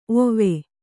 ♪ ovve